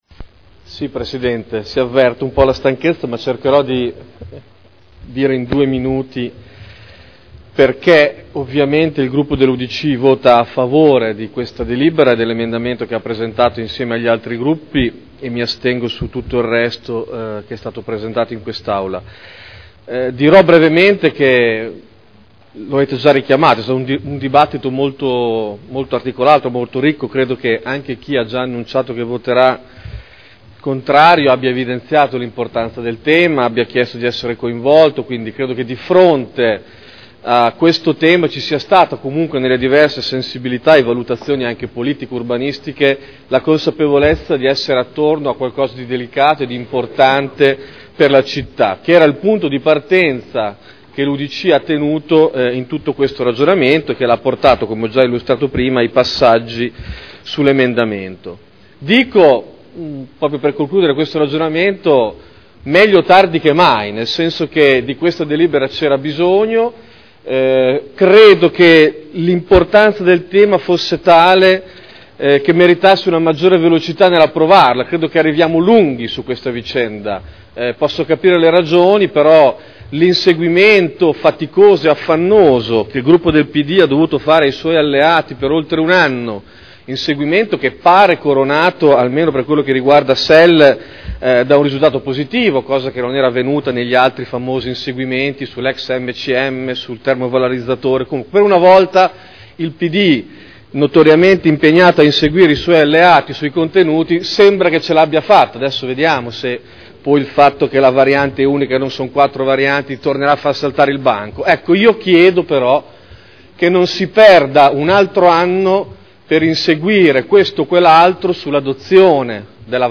Davide Torrini — Sito Audio Consiglio Comunale